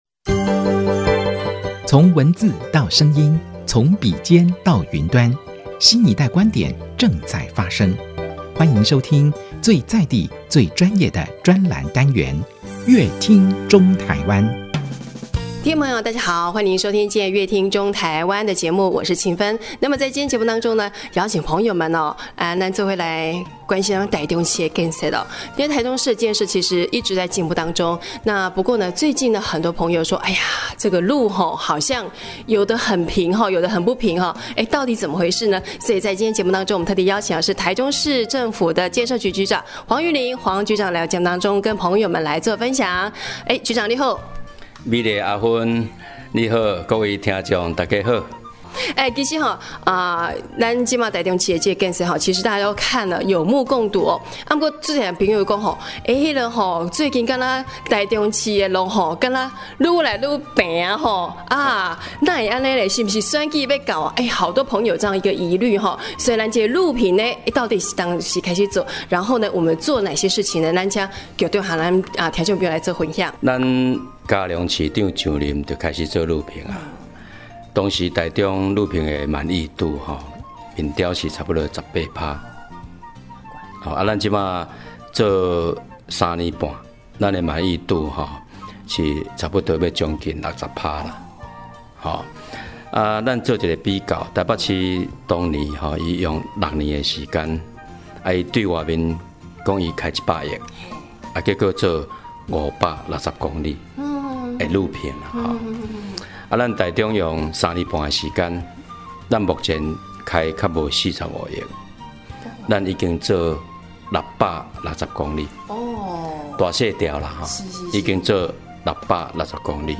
本集來賓：台中市政府建設局黃玉霖局長 本集主題：「路平真平?」